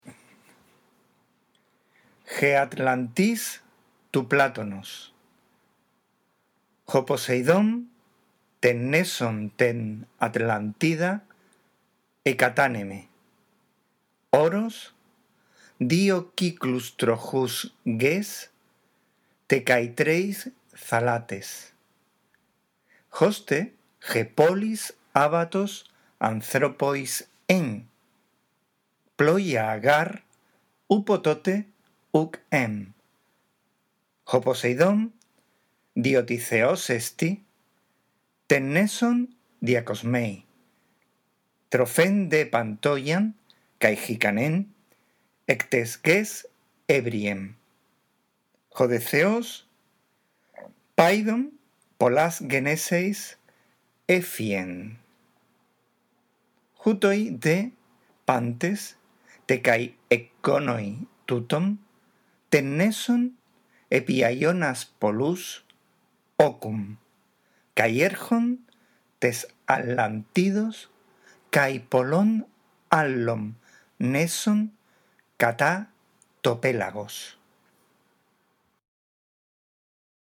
5. Lee, en primer lugar, despacio y en voz alta el texto. No te olvides de respetar los signos de puntuación. Puedes ayudarte con esta grabación: